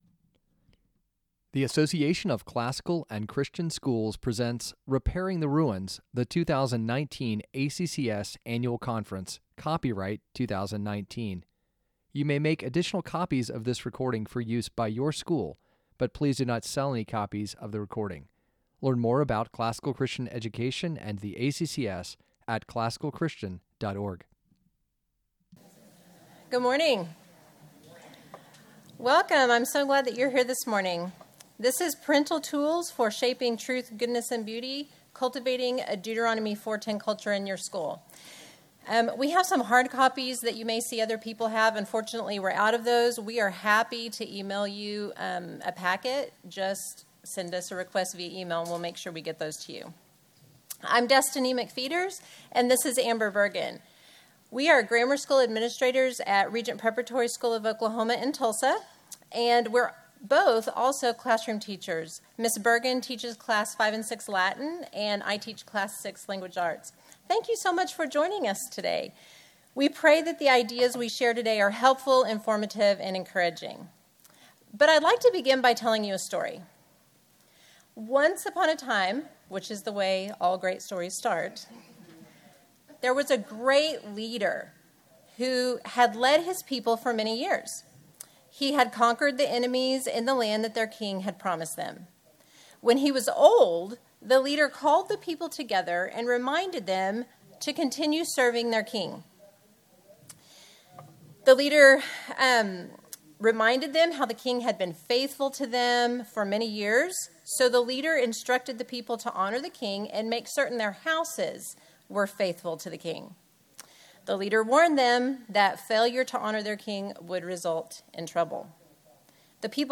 2019 Workshop Talk | 39:06 | All Grade Levels, Culture & Faith, Virtue, Character, Discipline